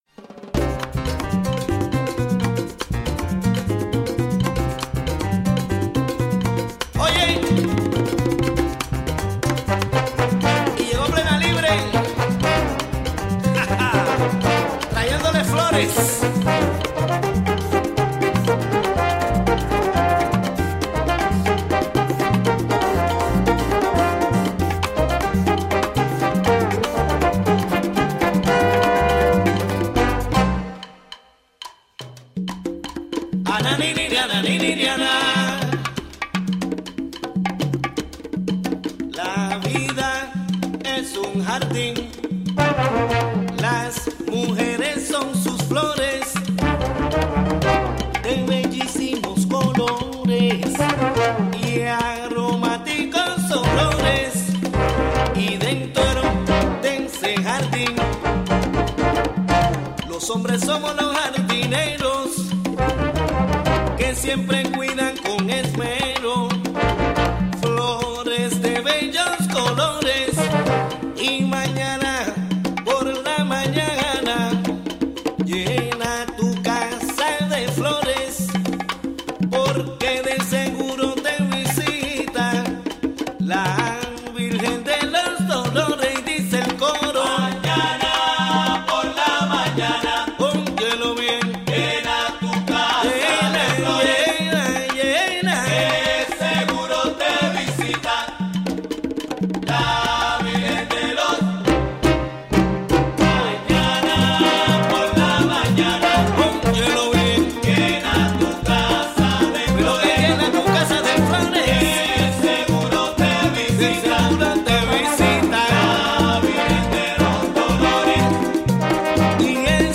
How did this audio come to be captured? WGXC Hudson Studio